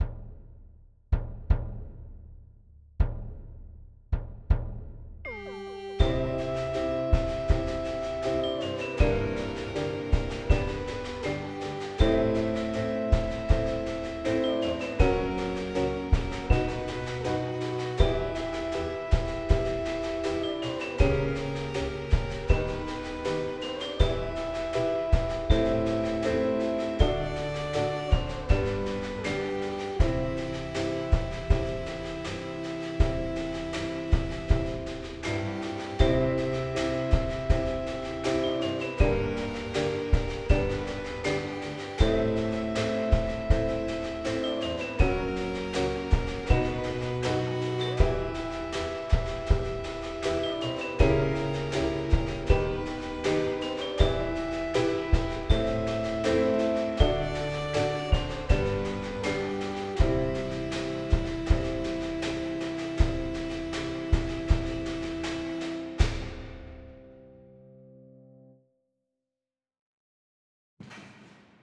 For no particular reason - some 60's sci fi music
Anyway, enough of the advertisements - in the process of dinking about with Reaper I produced this little snippet of music, which sounds a bit like something that might have shown up in one of the sadder scenes of the old Doctor Who TV show.
Sci Fi Piano Thang.mp3